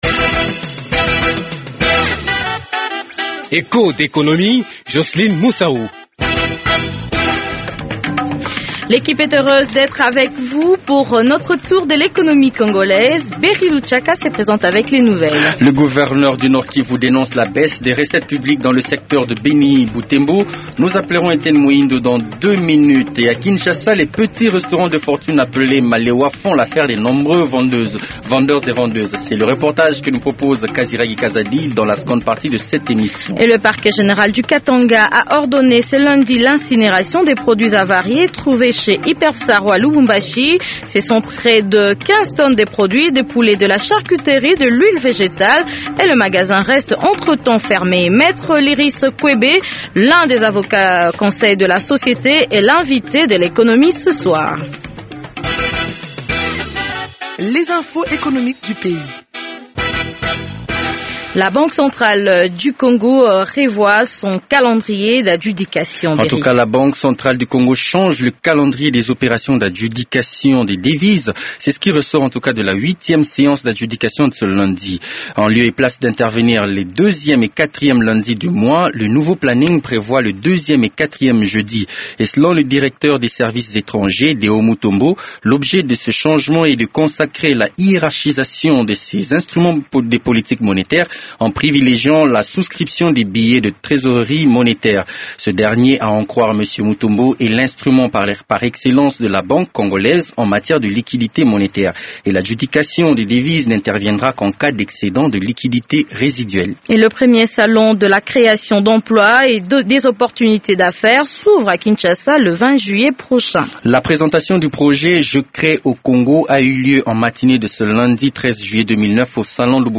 Les “Malewa” sont les petits restaurants de fortune parsemés à travers la ville de Kinshasa. C’est le reportage de la semaine qui commence dans ce magazine économique de Radio Okapi. A suivre aussi, le gouverneur du nord Kivu dénonce la baisse des recettes publiques dans le secteur de Butembo-Beni.
Echos d’économie pose la question à notre correspondant sur place.